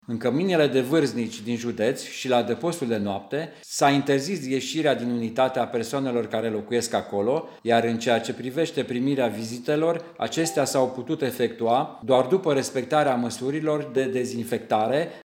Prefectul de Sibiu, Mircea Crețu, spune că niciun bătran din cămine nu mai are voie să iasă afară.
20mar-13-Prefect-Sibiu-batranii-din-camine-nu-pot-iesi.mp3